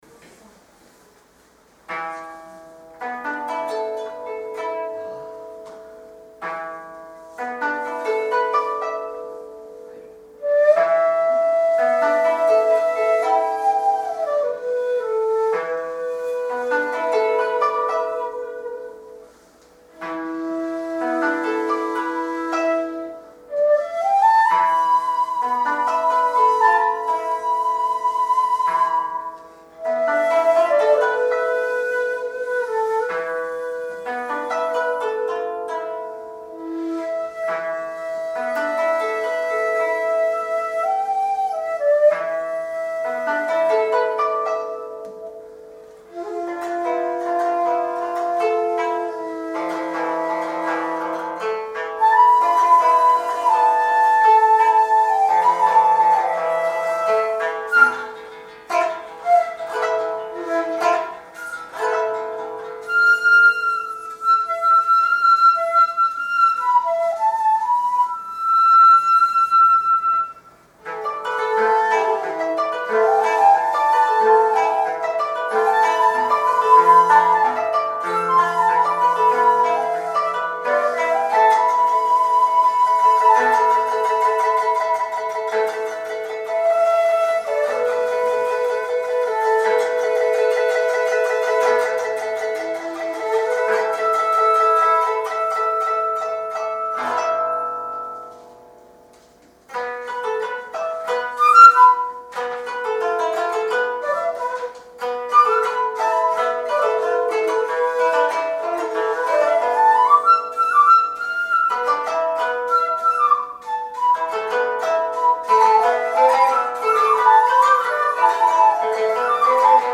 今回は趣向を変えて､後半部分を尺－ダーで演奏しました。
音はほとんど尺八音。